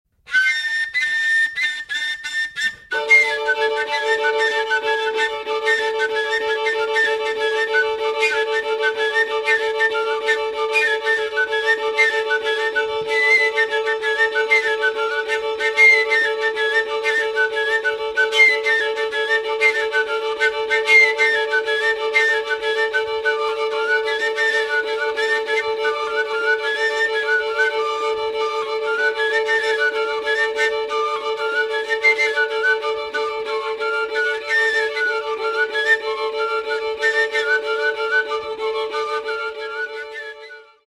Violin
Button Accordion, Tsimbl
Cello, Tilinca, Baraban
Genre: Klezmer.